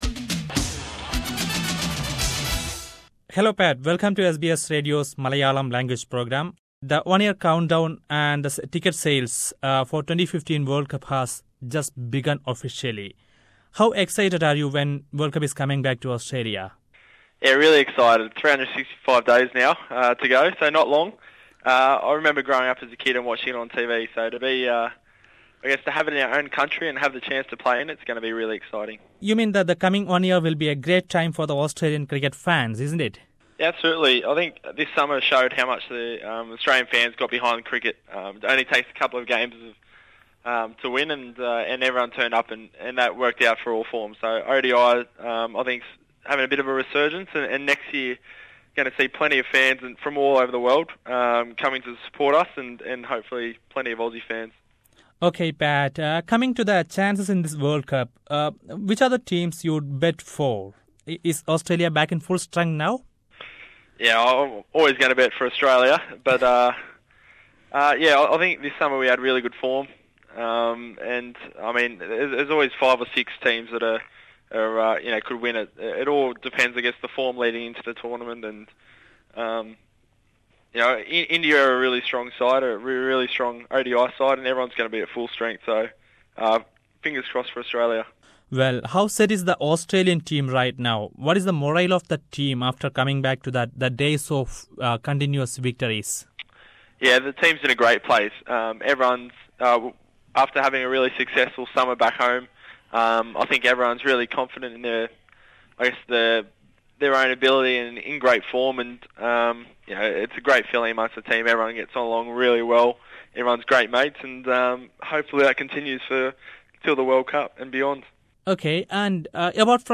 Listen to an interview with Patrick James Cummins, the young Australian fast bowler who speaks to SBS Malayalam Radio after the launch of the ticket sales about his excitement and expectations…